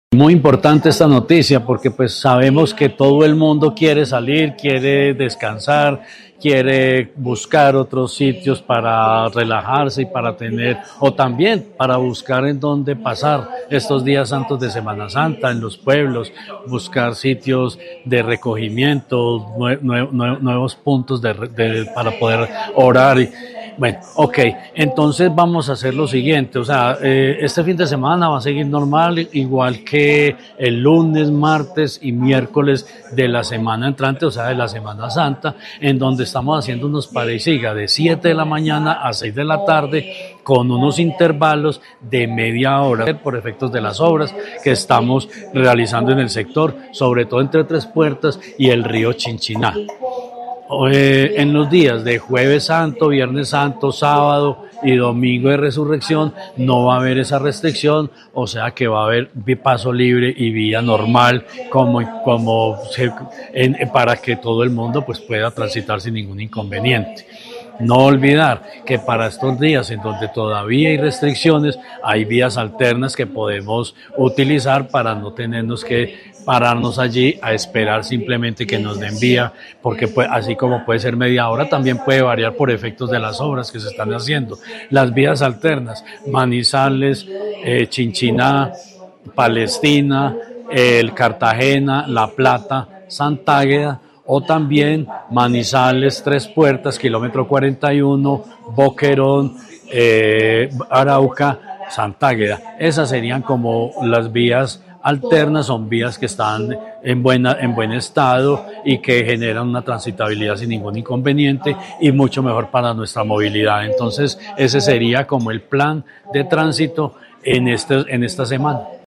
\n\n\n\n Descargar Video \n\n\n\n Descargar Audio \n\n\n\n Jorge Ricardo Gutiérrez, secretario de Infraestructura de Caldas.